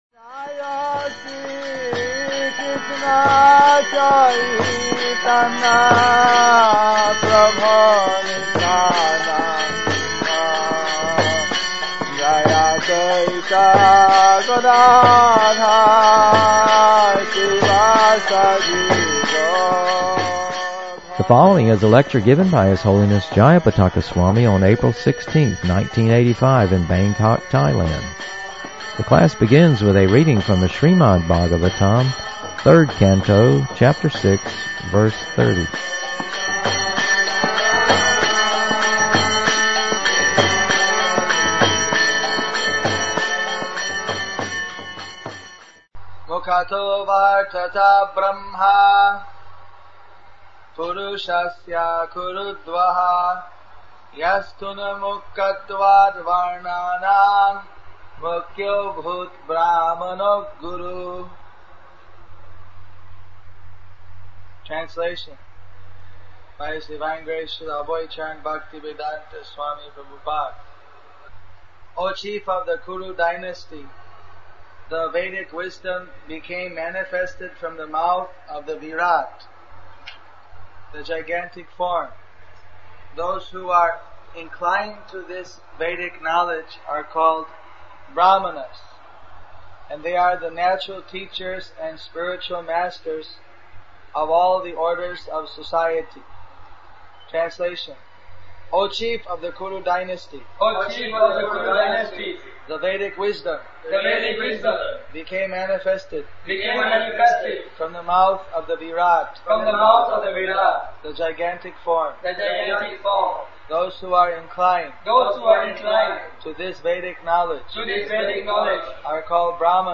Lectures
The class begins with a reading from the srimad bhagavatam, 3rd canto, chapter 6, verse 30. mukhato ’vartata brahma purusasya kurudvaha yas tunmukhatvad varnanam mukhyo bhud brahmano guruh Translation by His Divine grace A.C.Bhaktivedanta Srila Prabhupada.